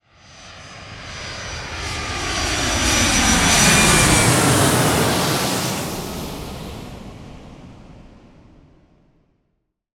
دانلود آهنگ سوت هواپیما 5 از افکت صوتی حمل و نقل
دانلود صدای سوت هواپیما 5 از ساعد نیوز با لینک مستقیم و کیفیت بالا
جلوه های صوتی